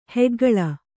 ♪ heggaḷa